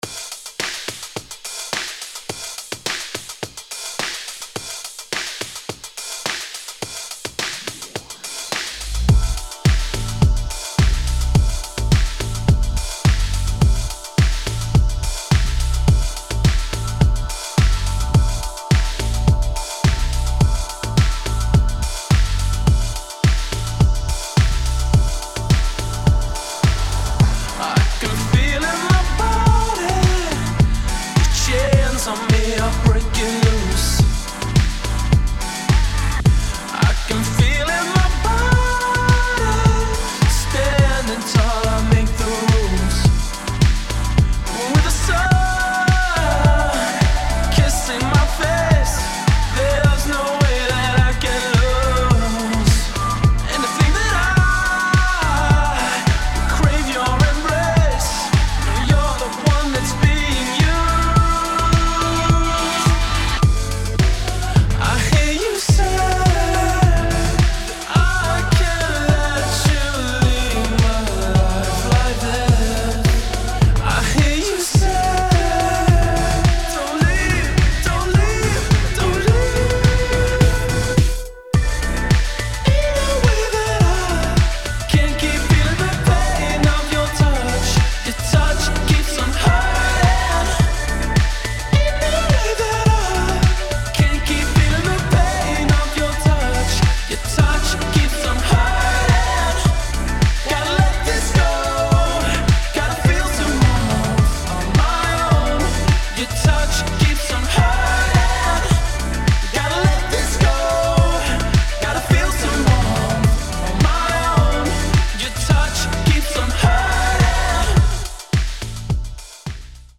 2. ELECTRONIC | TECHNO
[ ROCK | EDIT ]